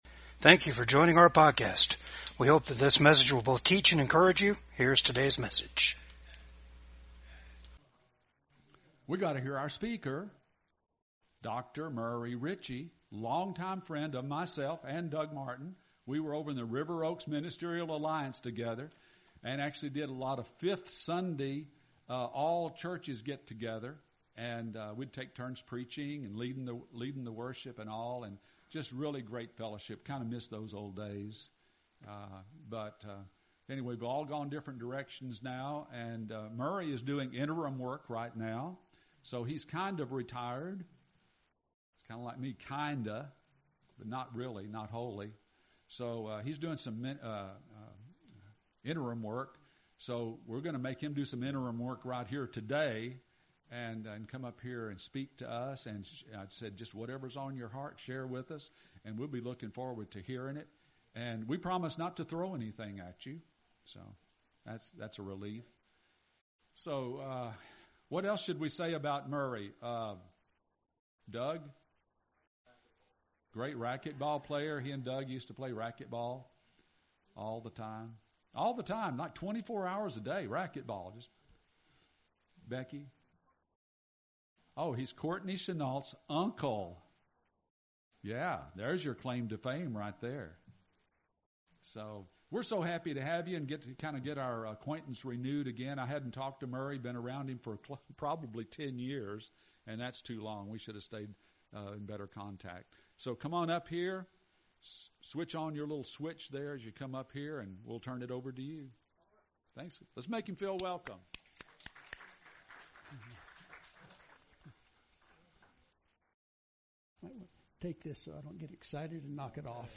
Ezekiel 37:1-14 Service Type: VCAG WEDNESDAY SERVICE Topics